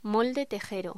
Locución: Molde tejero
voz